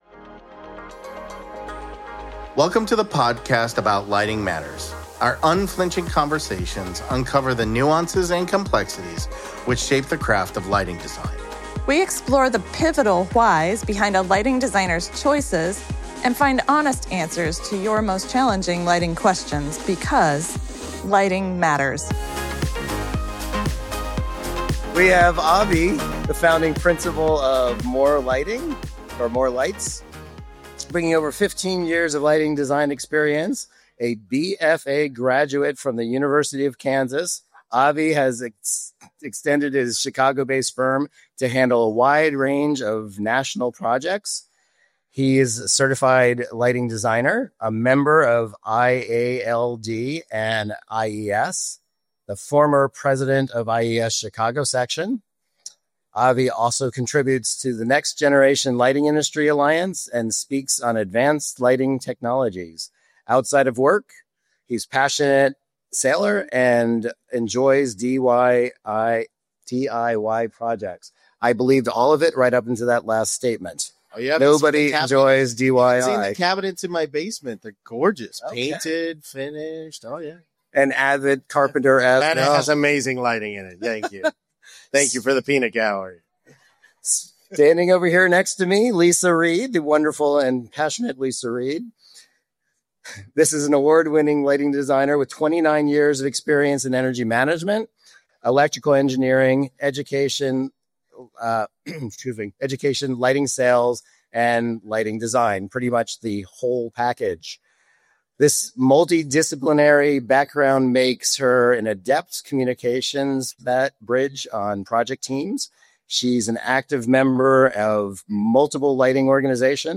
Episode 28: Live at ArchLight: Taking the Stage and Taking a Stance